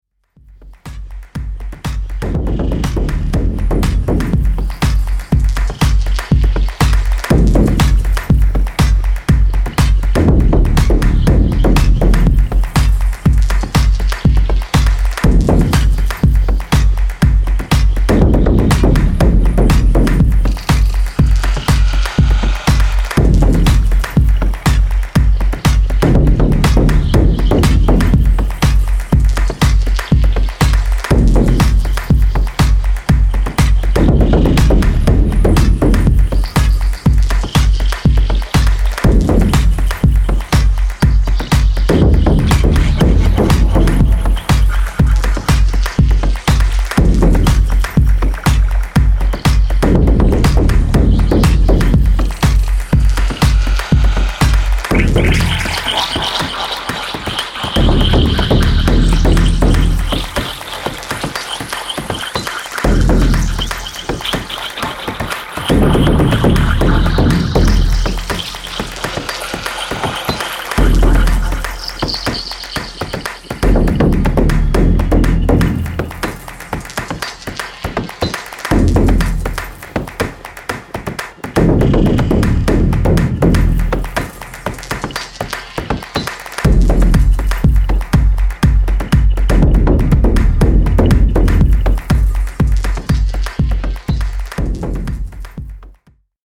121 BPM